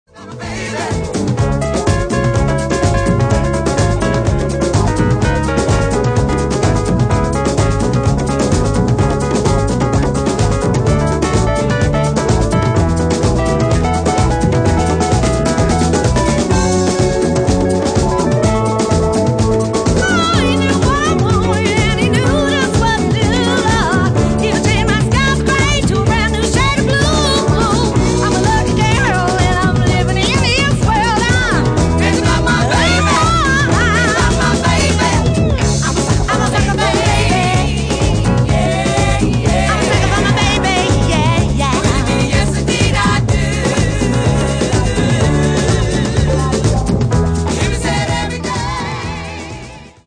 Genere:   Soul Funky